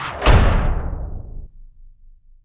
LC IMP SLAM 4.WAV